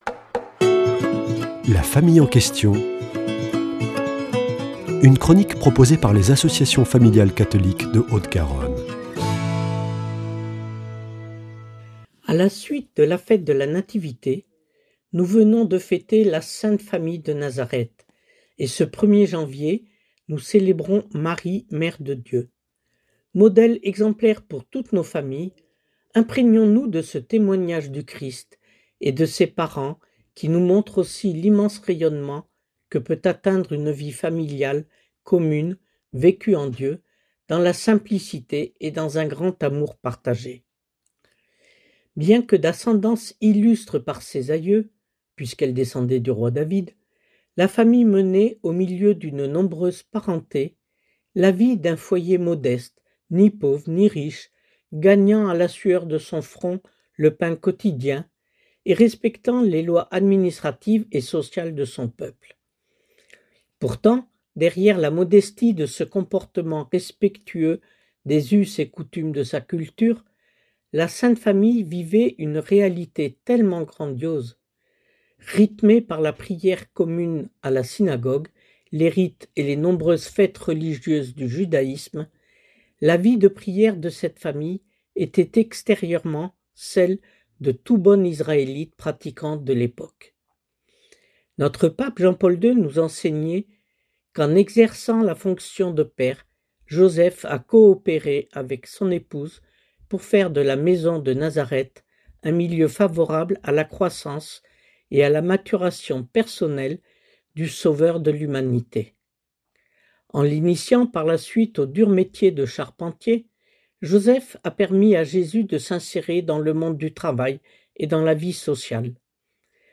mercredi 31 décembre 2025 Chronique La famille en question Durée 3 min